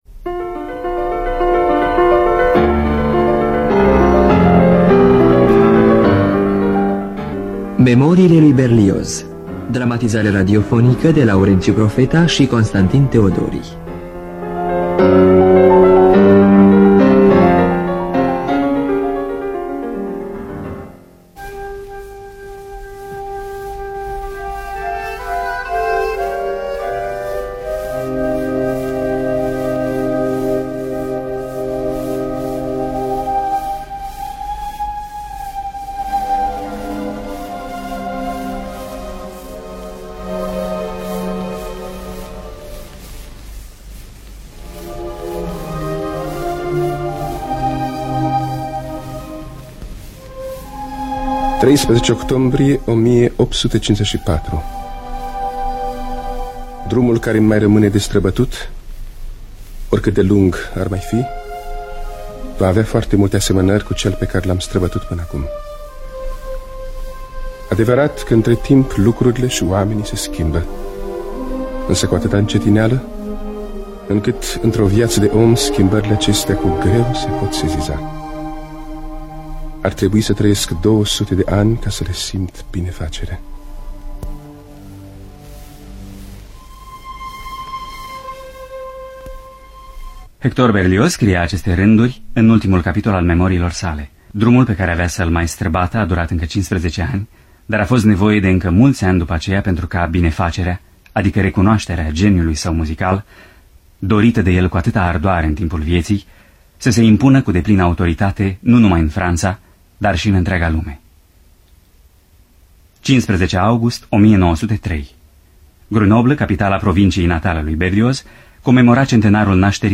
Scenariu radiofonic de Laurenţiu Profeta şi Constantin Teodori.